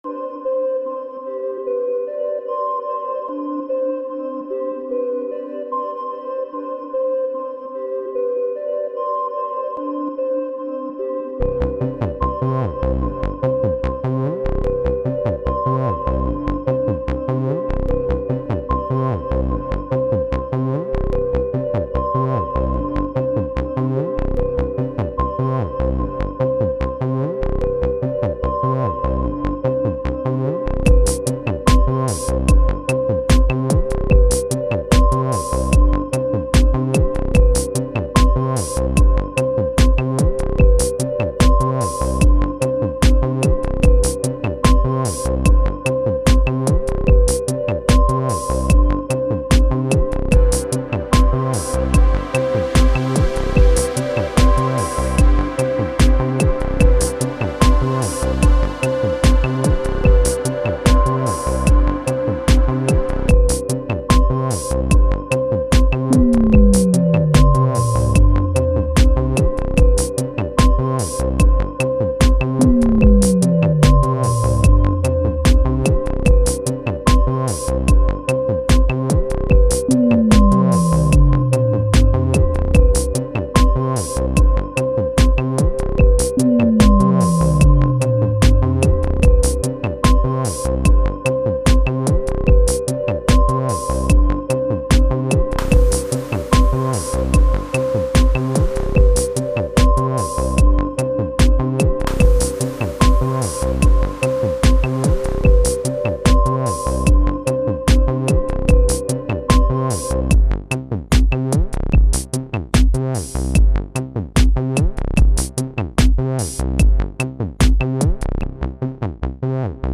Žánr: Electro/Dance
Elektronika se snoubí s klasickou hudbou